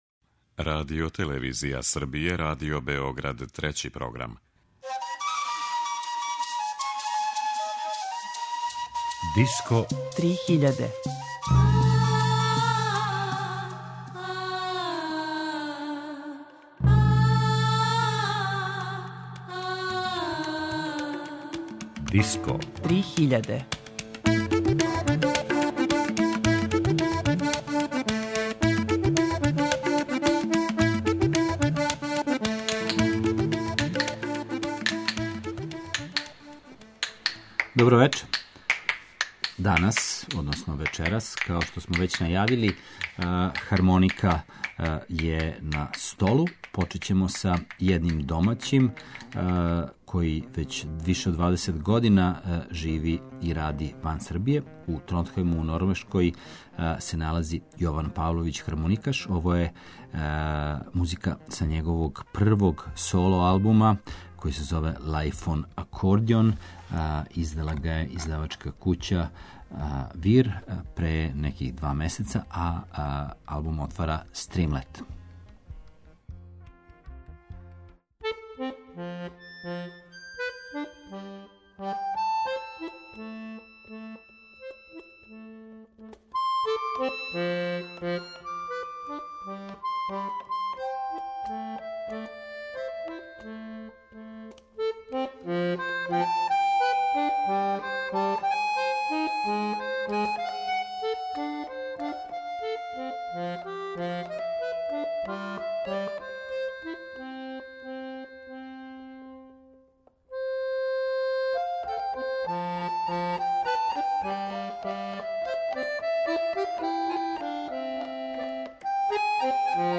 Хармоника
Један од инструмената који се снажно везује за традиционалну и светску музику је хармоника. У вечерашњој емисији представићемо три нова албума на којима овај инструмент доминира.